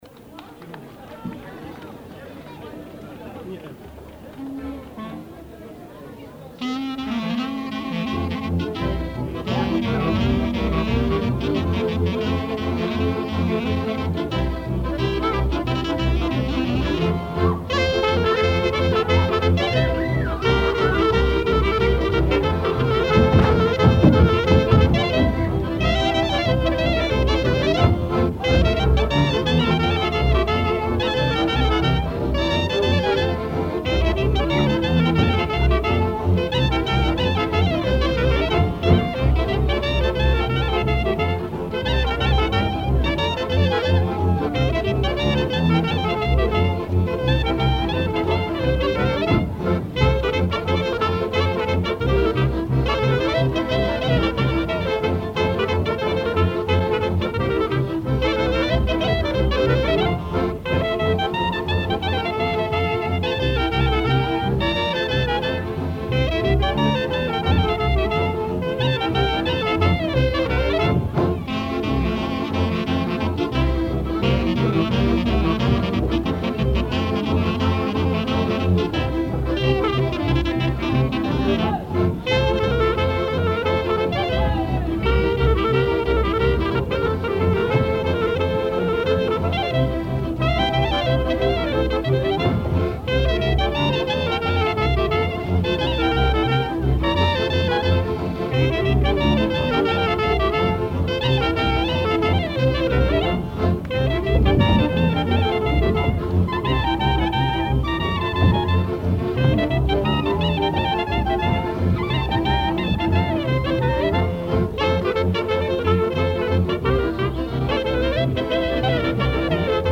Vingt et unième pièce - Duo de clarinettes